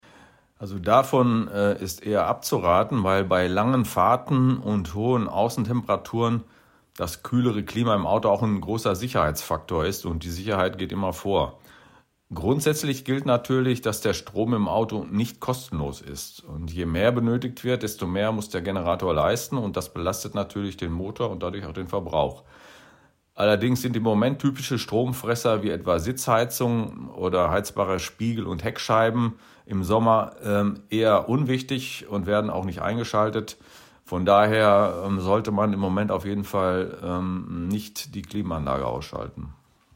radioEXPERTEN - Ihr perfekter Interviewpartner